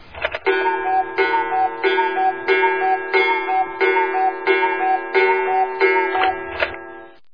SFX布谷鸟钟整点报时的声音音效下载